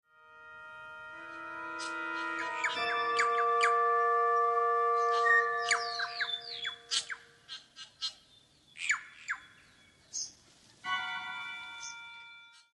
for organ and birdsong